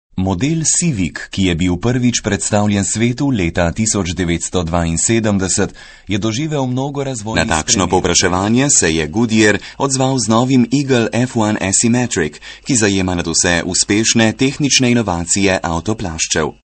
Sprecher slowenisch für TV / Rundfunk / Industrie / Werbung. Muttersprachler.
Sprechprobe: Industrie (Muttersprache):
Professionell voice over artist from Slowenia.